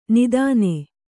♪ nidāne